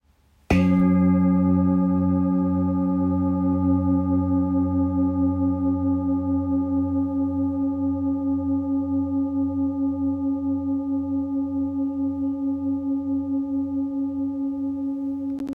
Large Etched Bowl with Seven Chakra Symbols Tibetan – 39cm
Rich and resonant, perfect for meditation, sound baths, and energy work.
Tibetan-chakra-nada.m4a